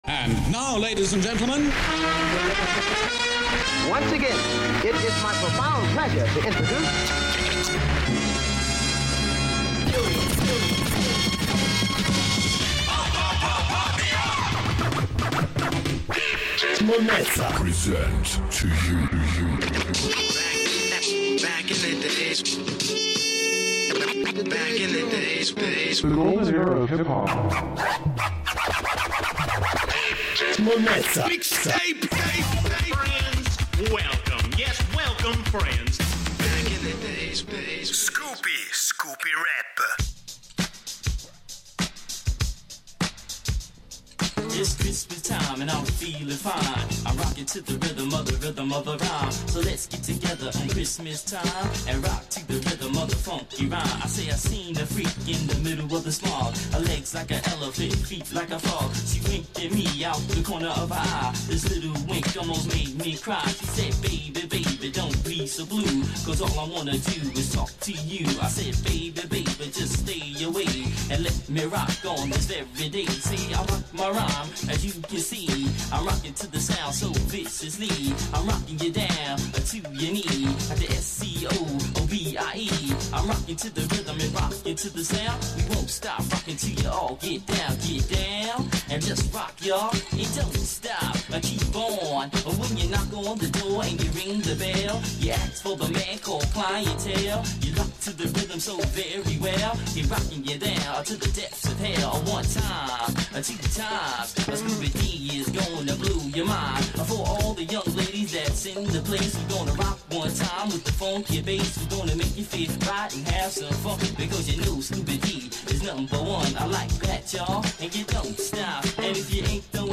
un pezzo proto rap
blues
The golden era of Hip Hop